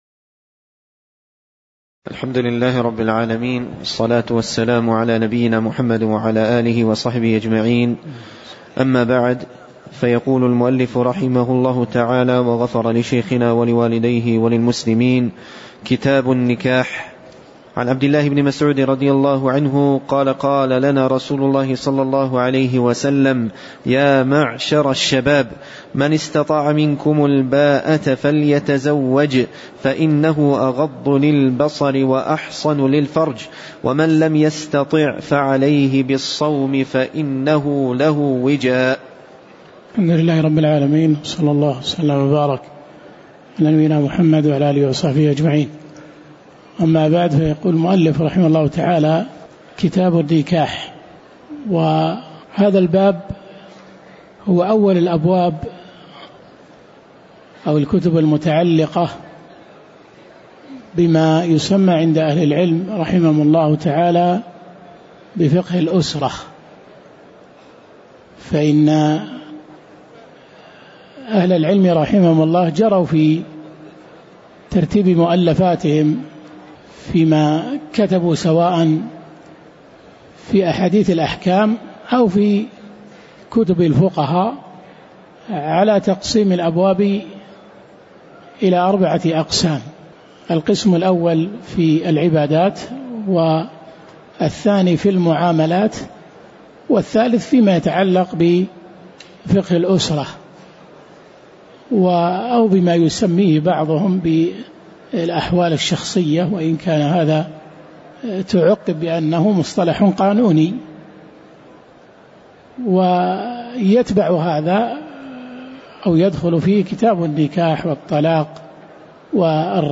تاريخ النشر ٦ محرم ١٤٤٠ هـ المكان: المسجد النبوي الشيخ